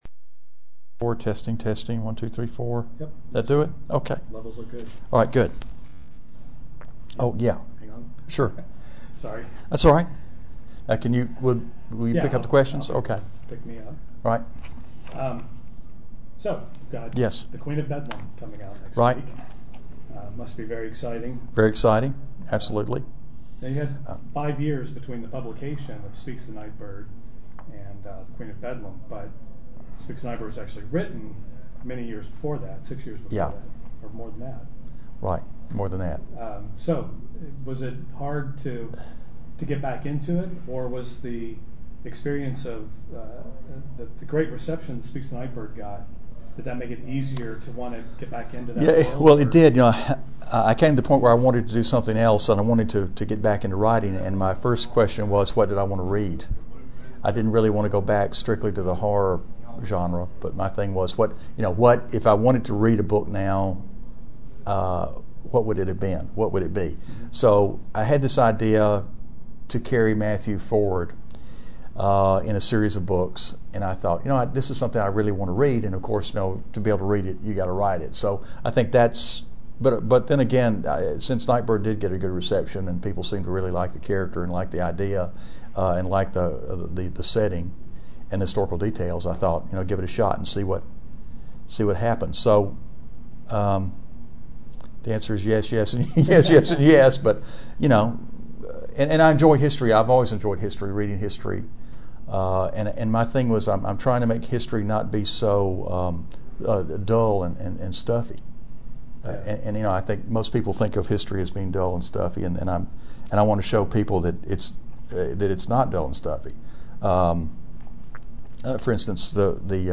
You can read the article on their website. 11/26/07 — New McCammon interview On October 15, 2007, I sat down with Robert McCammon to talk about The Queen of Bedlam.